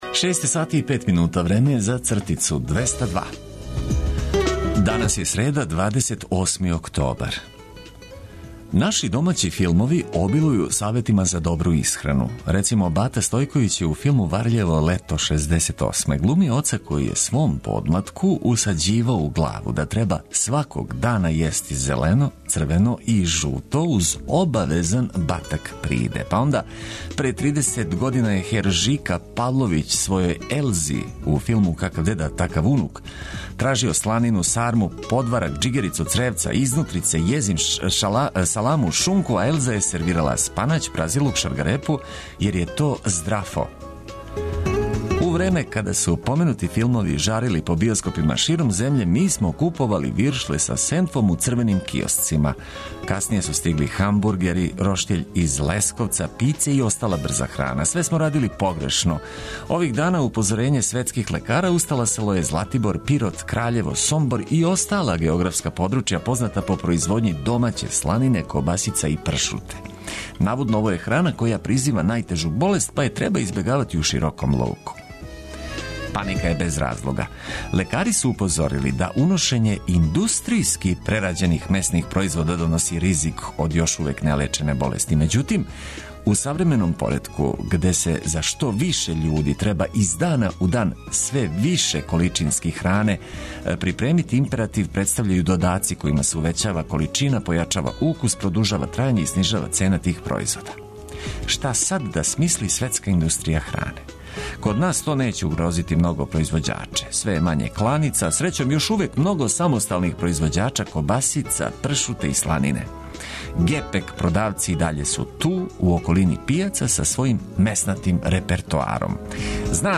Овога јутра, у сред среде, будићемо вас и упознавати са актуелним информацијама од којих ћете имати користи, а све то уз много добре музике за буђење.